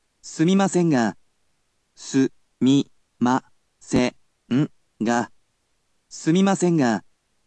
You’ll be able to hear an organic voice in another resource, but for now, he’ll definitely help you learn whilst at least hearing the words and learning to pronounce them.